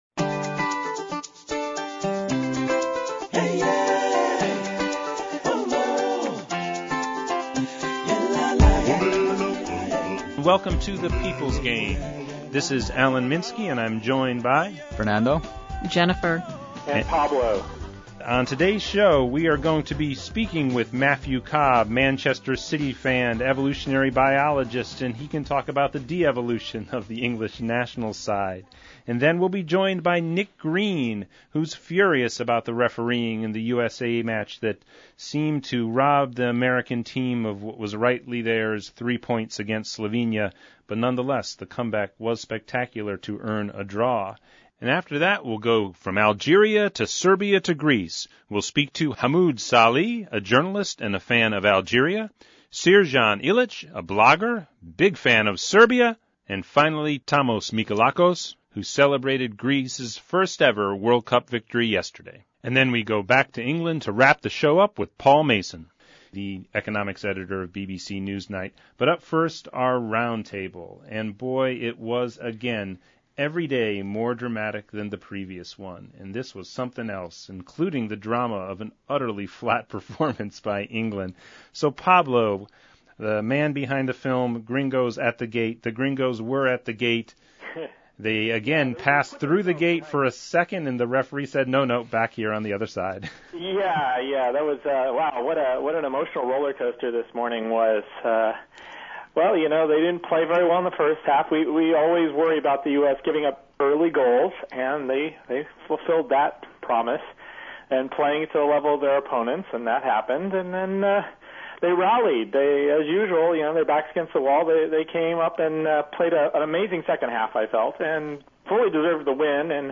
Lastly, the BBC’s Paul Mason says the chorus of his countrymen calling for Fabio Capello’s head are out of tune – the blame for England’s misery lies elsewhere.